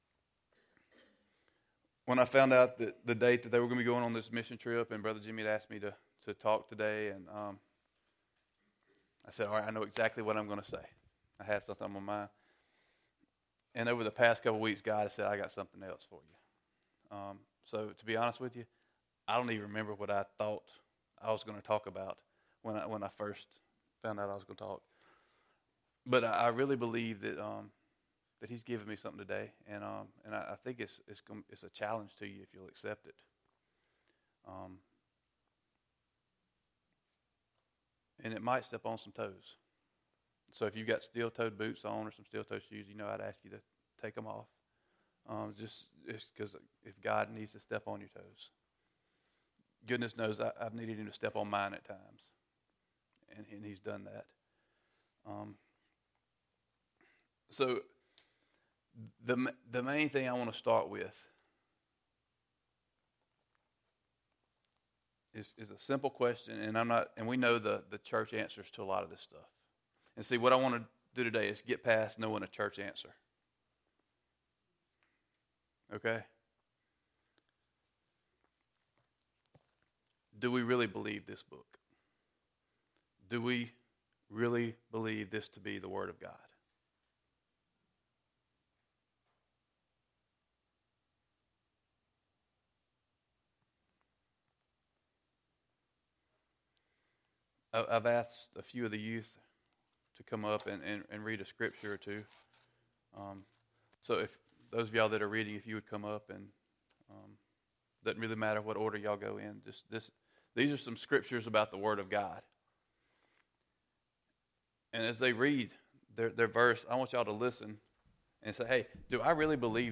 SERMON ARCHIVE